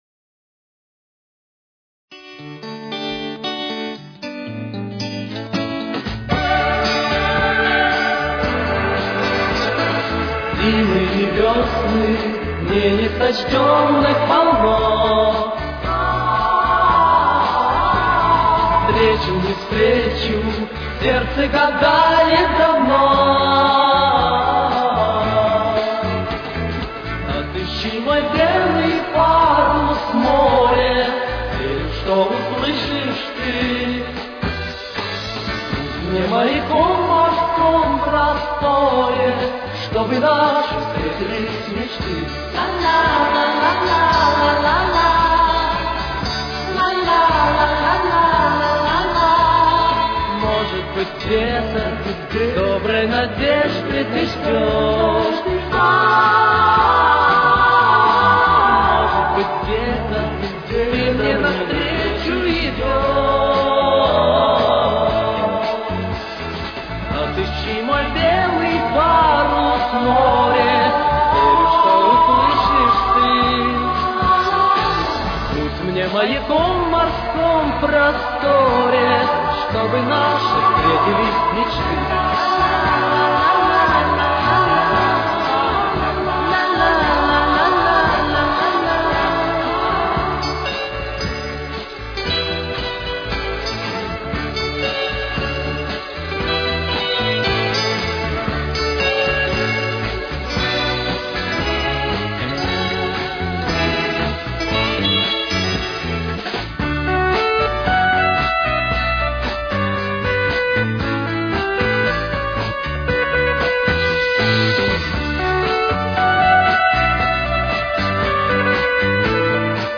с очень низким качеством (16 – 32 кБит/с)
Тональность: Ре мажор. Темп: 116.